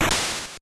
CheepCheepJump.wav